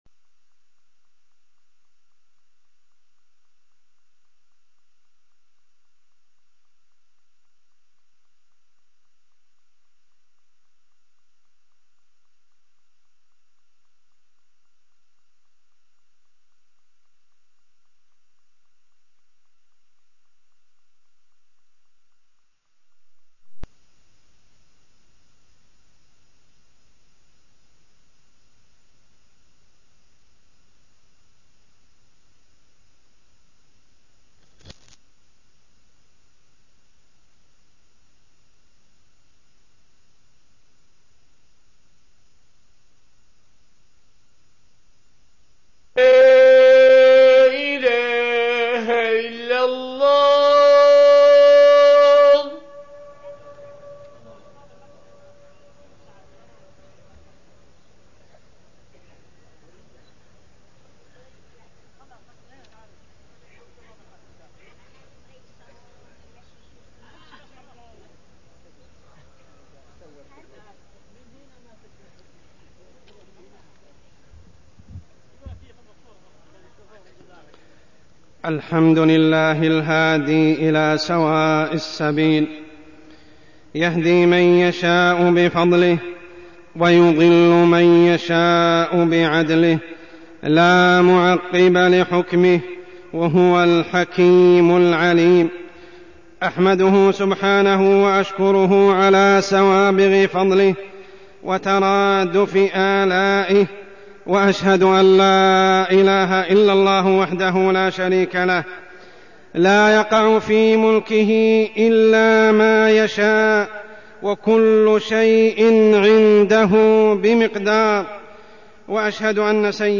تاريخ النشر ٢١ شوال ١٤١٧ هـ المكان: المسجد الحرام الشيخ: عمر السبيل عمر السبيل إتيان السحرة والمشعوذين والكهنة والعرافيين